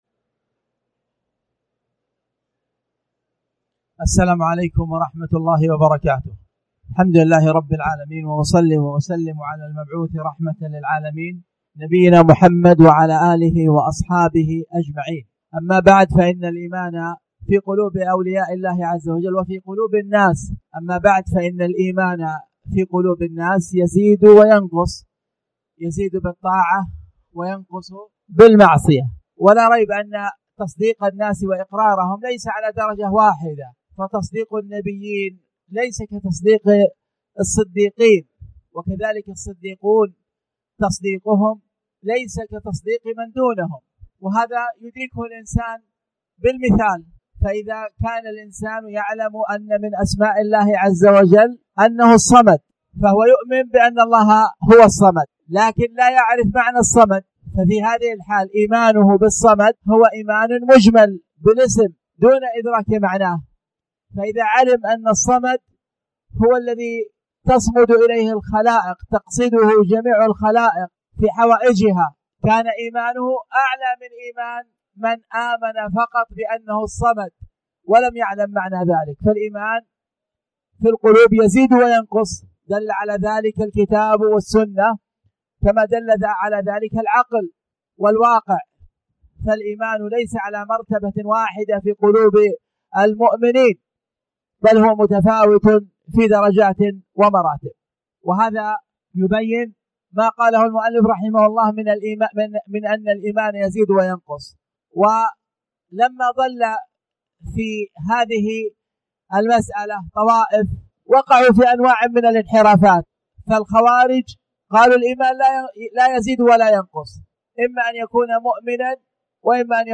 تاريخ النشر ٣ شعبان ١٤٣٩ هـ المكان: المسجد الحرام الشيخ